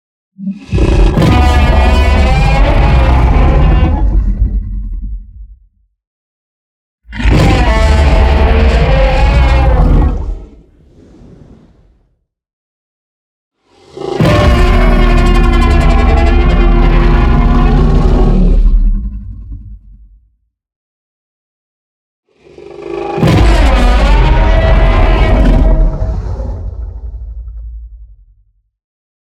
Серия звуков с ревом тираннозавра